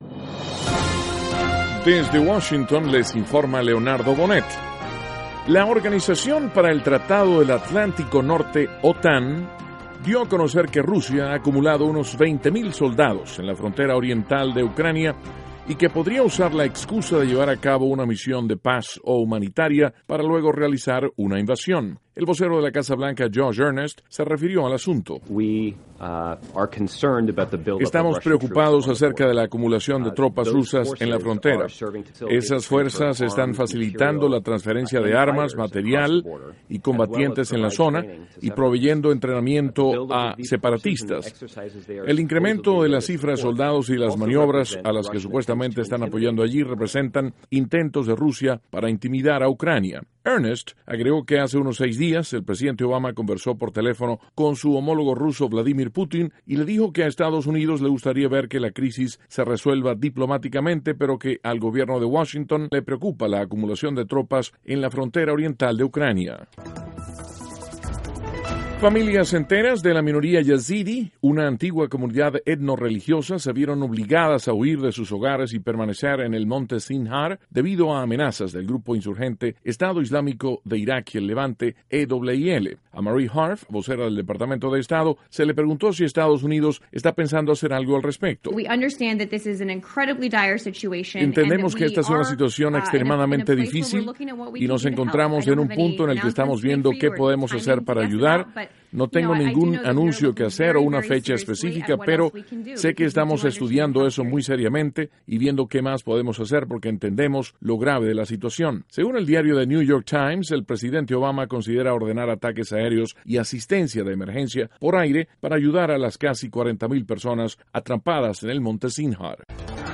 NOTICIAS - 7 DE AGOSTO, 2014